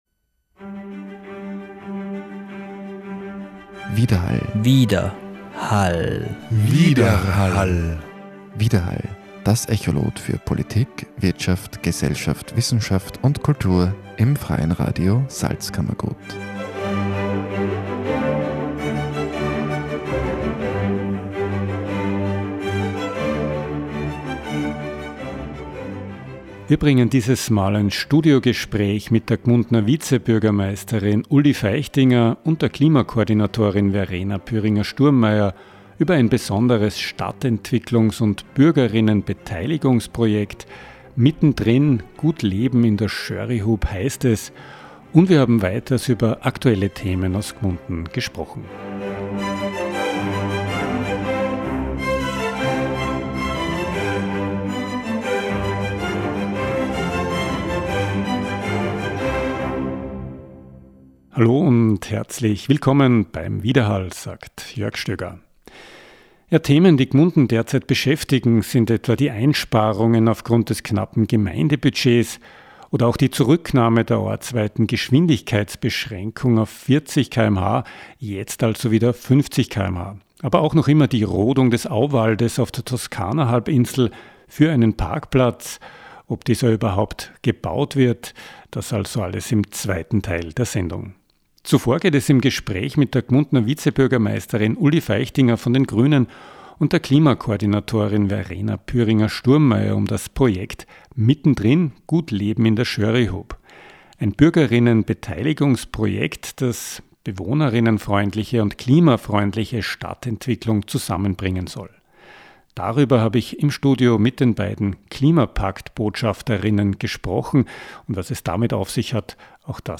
Studiogespräch